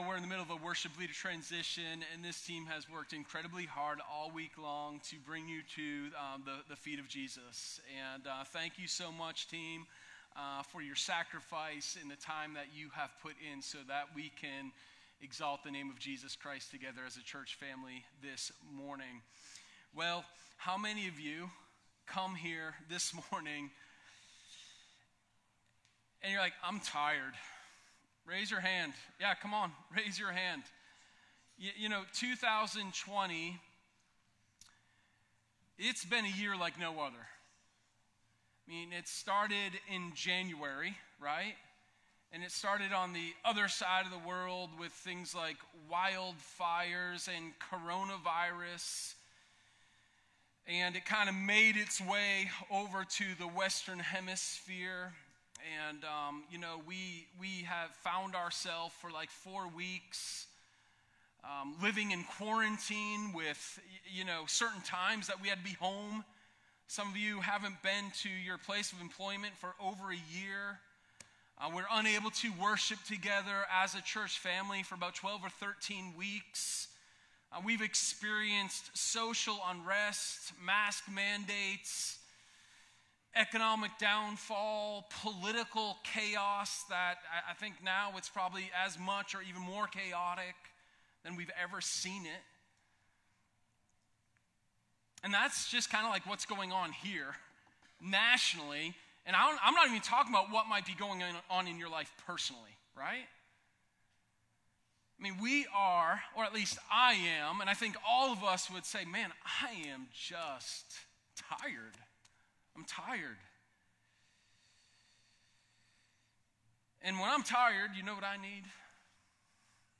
Sermon1108_Rest-in-the-Redeemer.m4a